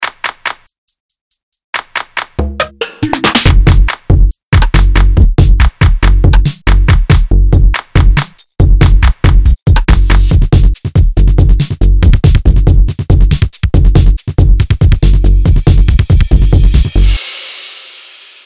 Download Instruments sound effect for free.
Instruments